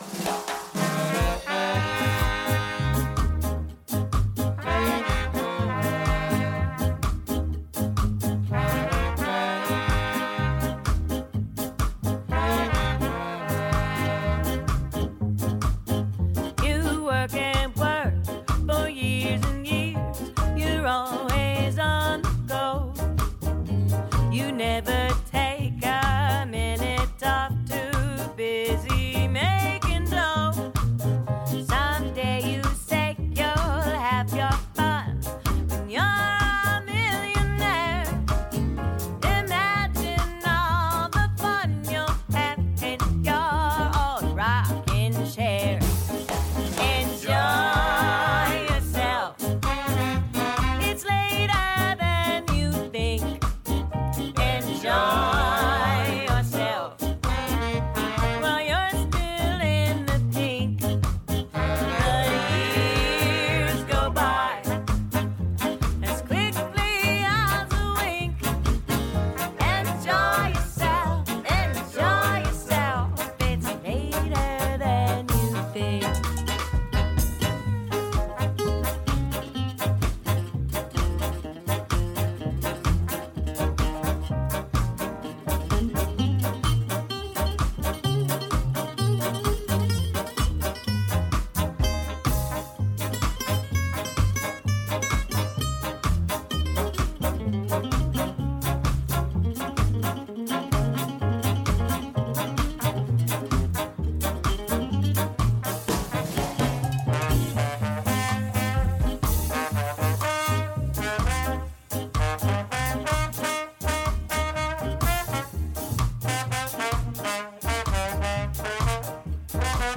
ΜΟΥΣΙΚΗ